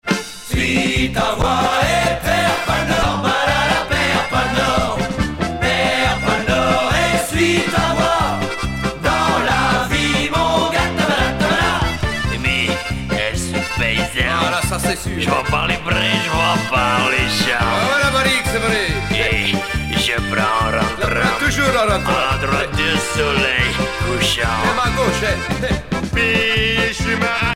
danse : marche
Genre strophique
Pièce musicale éditée